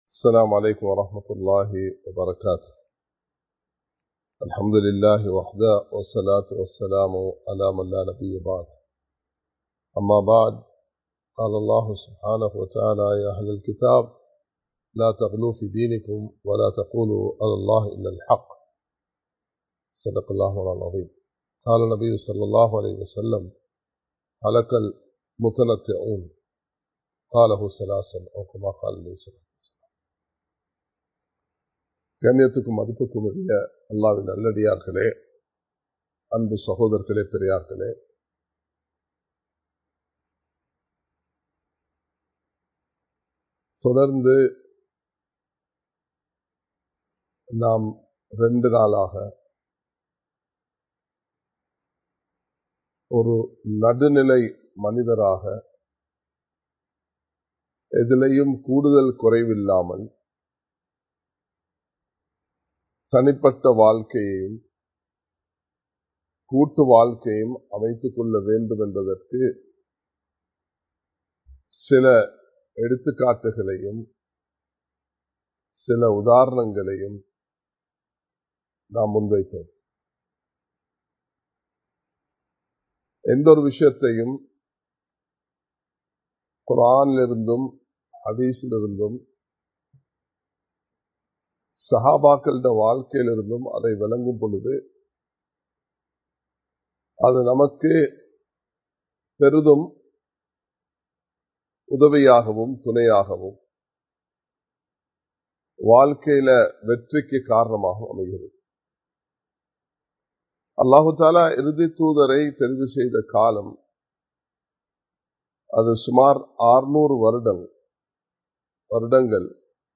நிதானமாக நடந்து கொள்வோம் (பகுதி 3) | Audio Bayans | All Ceylon Muslim Youth Community | Addalaichenai
Live Stream